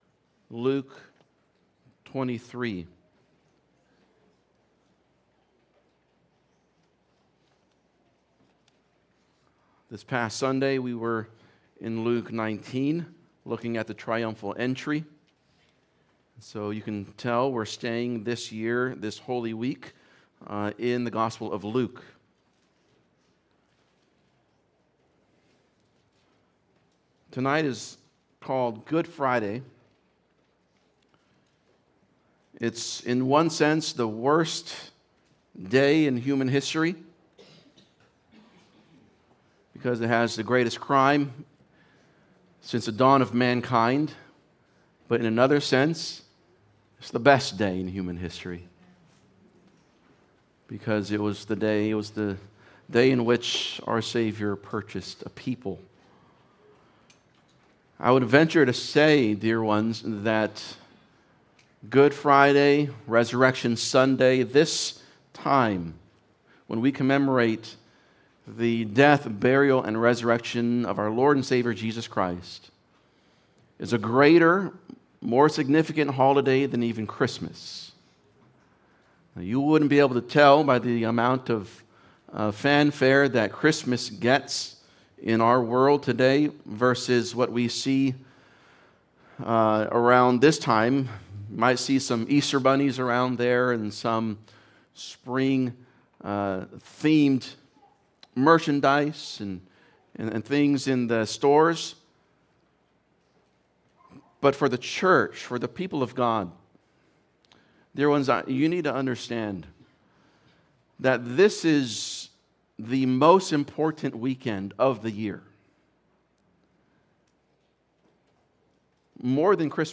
Good Friday | The Ultimate Display of Grace | Redeemer Bible Church